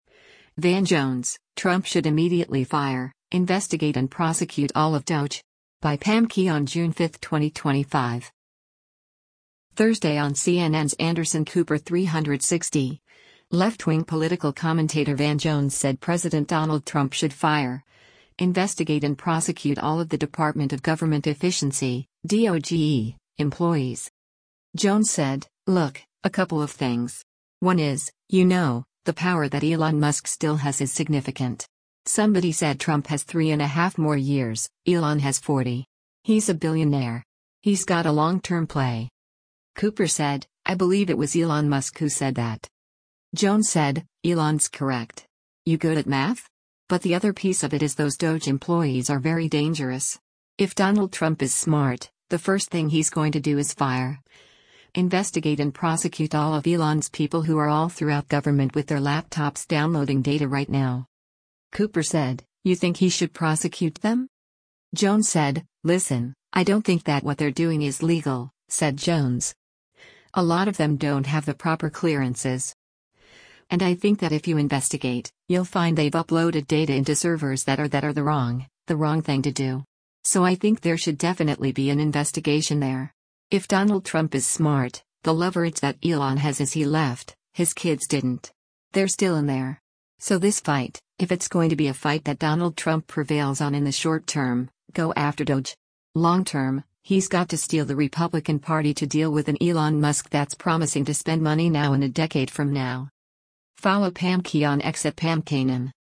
Thursday on CNN’s “Anderson Cooper 360,” left-wing political commentator Van Jones said President Donald Trump should “fire, investigate and prosecute” all of the Department of Government Efficiency (DOGE) employees.